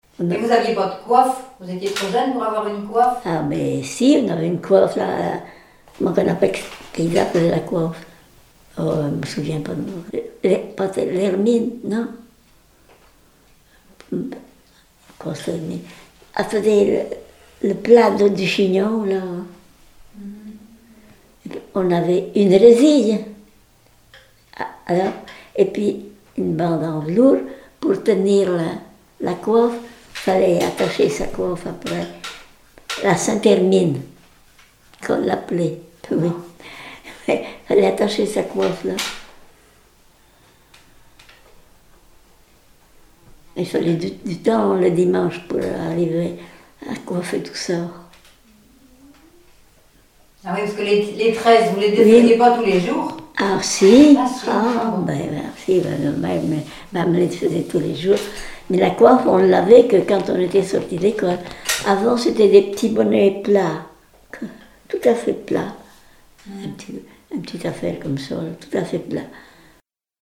Témoignages sur la vie à la ferme
Catégorie Témoignage